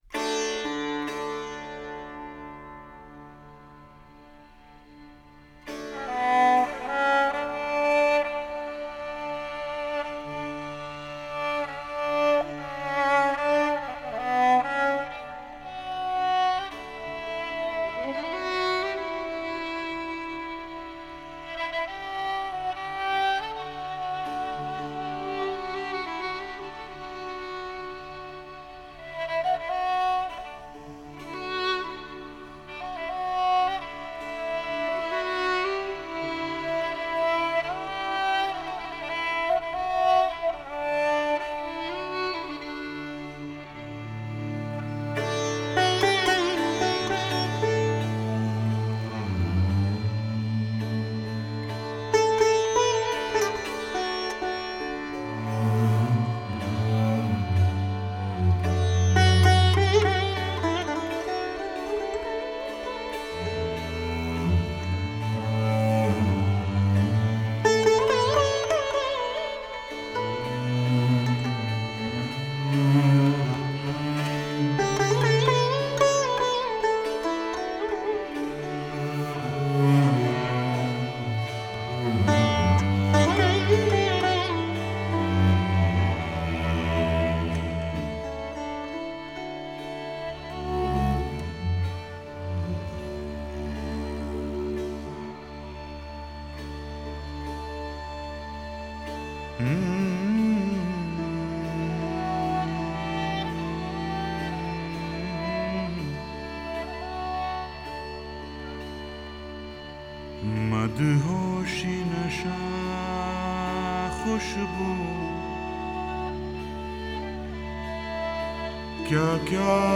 Жанр: Classical.